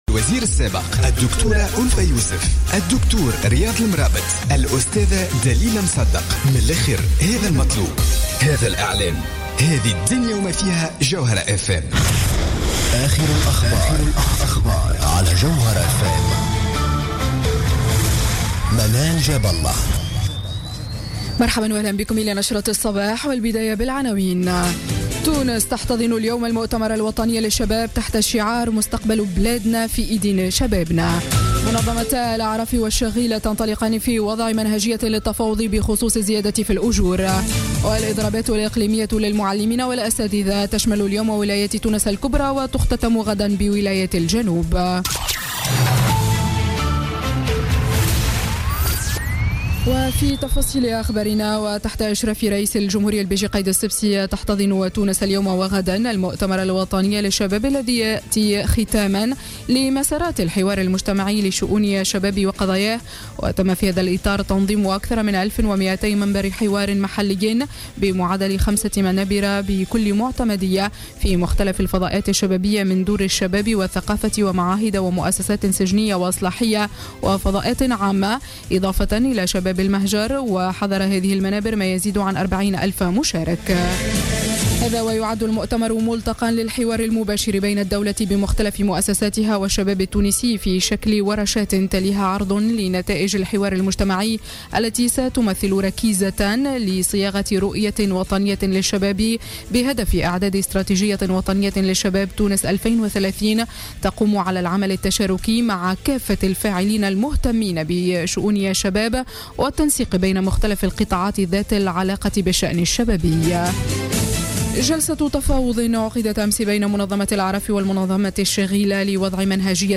نشرة أخبار السابعة صباحا ليوم الثلاثاء 27 ديسمبر 2016